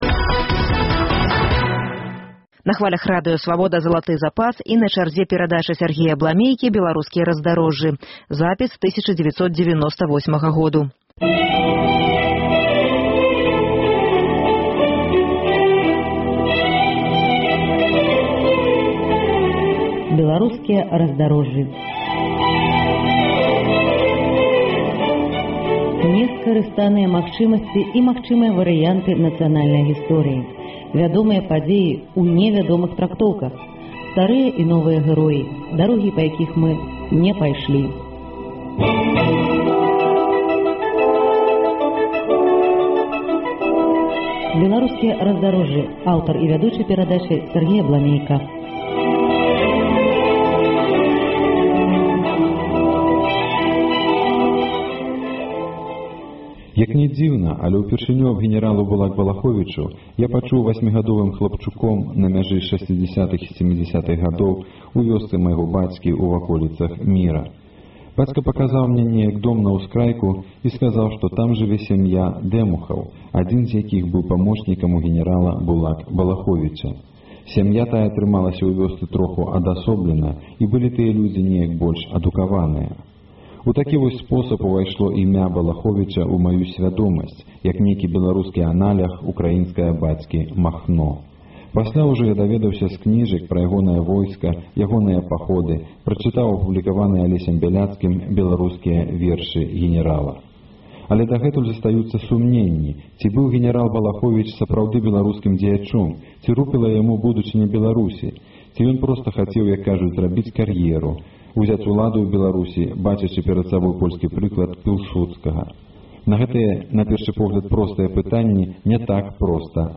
Перадача "Беларускія раздарожжы". Архіўны запіс 1998 году.